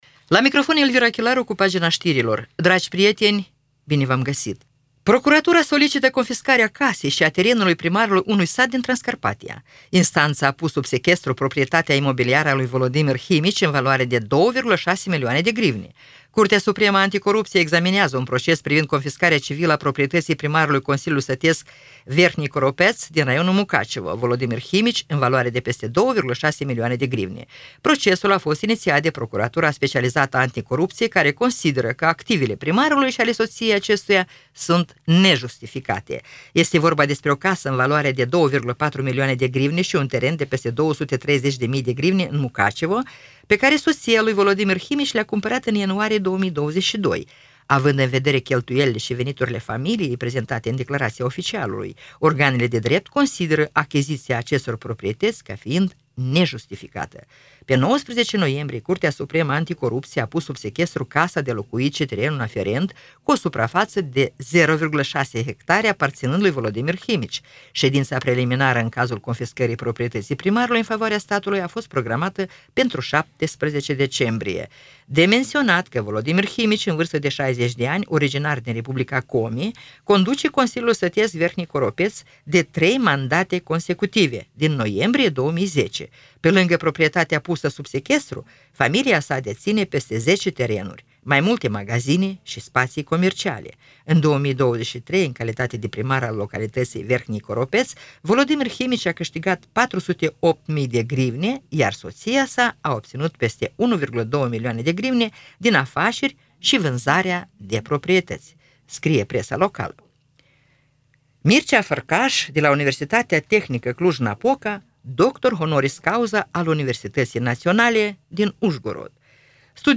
Știri Radio Ujgorod – 26.11.2024
Știri de la Radio Ujgorod.